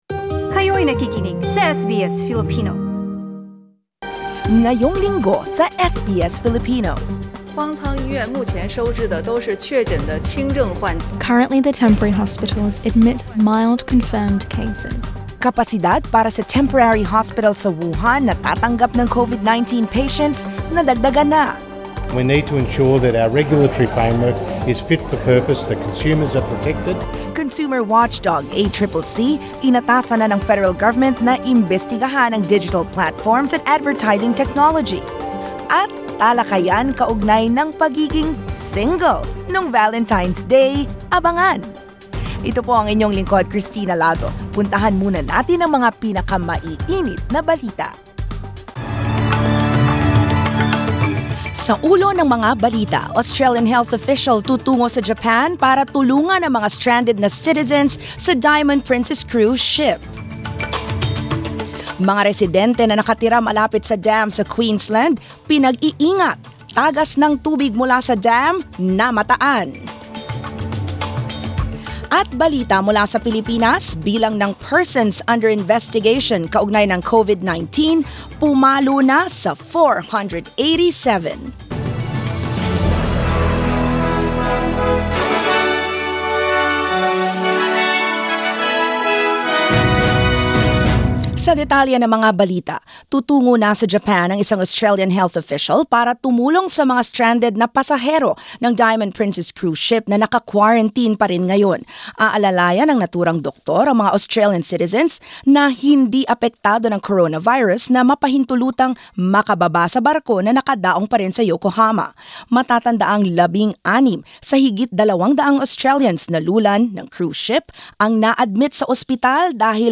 SBS News in Filipino, Sunday 16 February